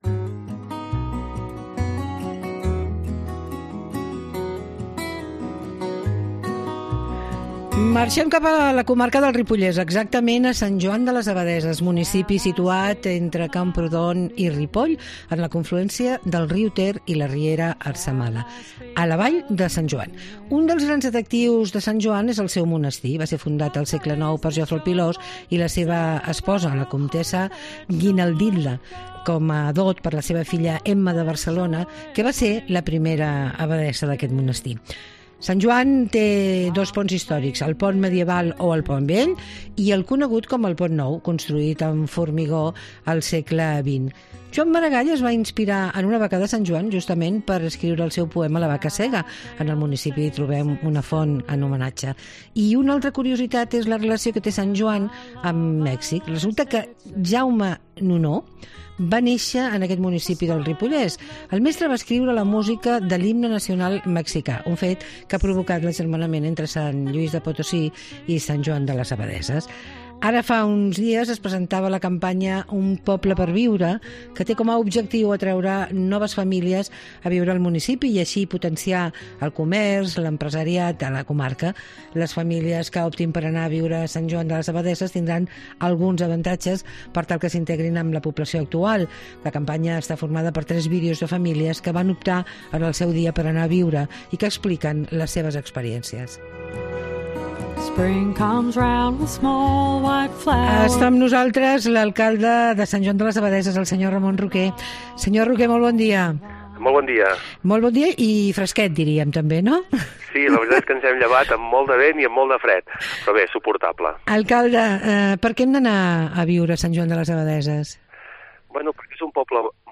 AUDIO: Ramon Roqué, alcalde de Sant Joan de les Abadesses, ens parla de la seva campanya, “Un poble per viure”